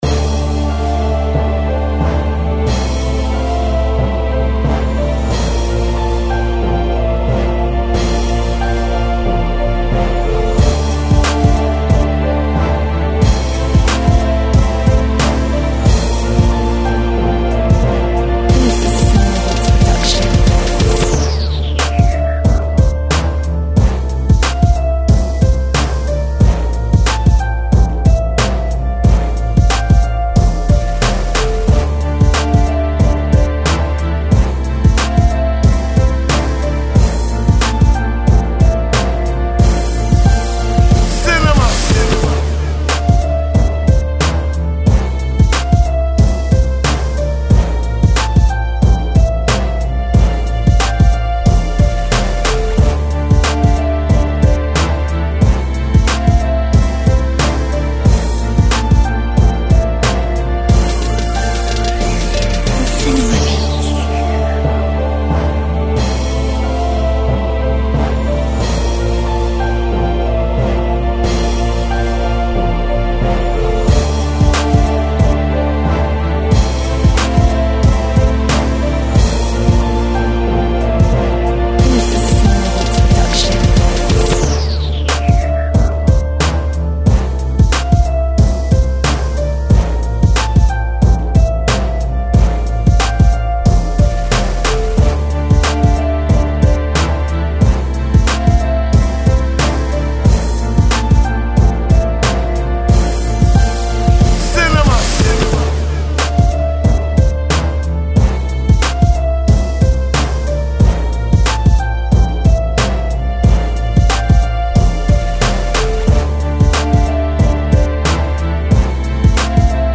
ловите лучшие 10 минусов, пряных, качающих, сочных.
минус 7